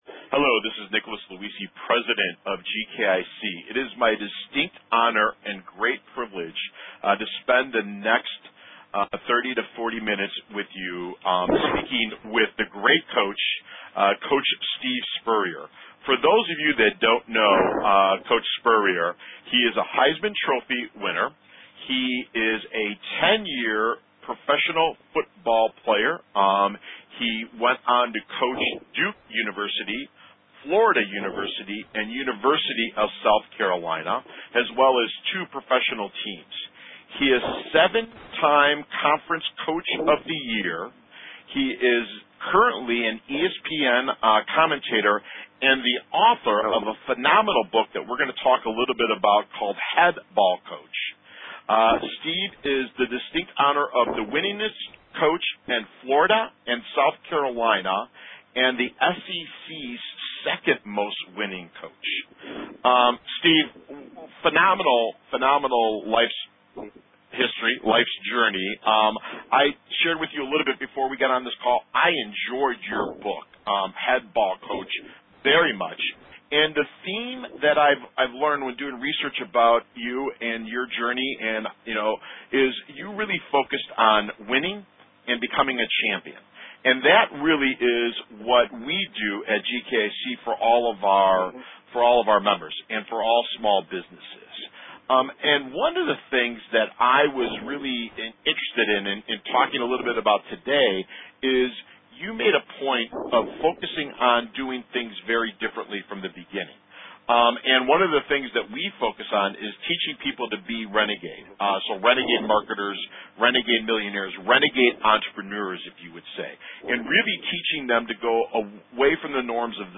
Steve+Spurrier+Interview-mixed.mp3